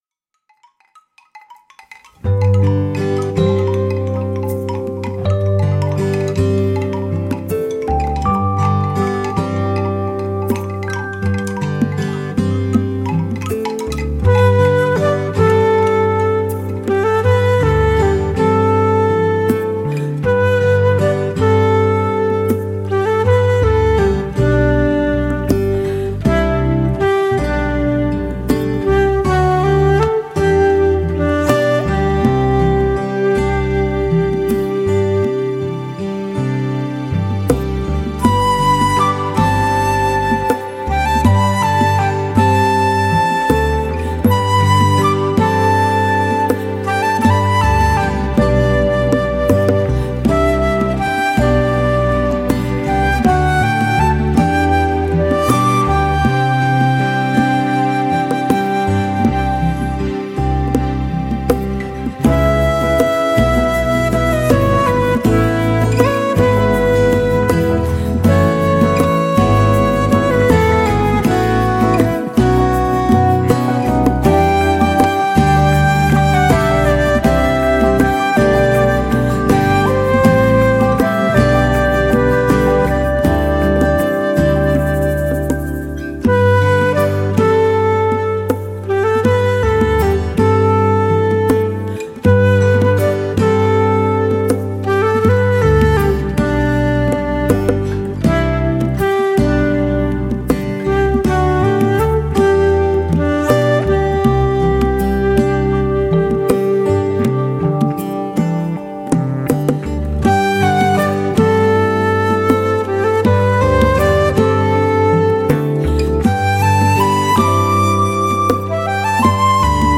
Originaalhelistik: G-duur
instrumentaalpala flöödiga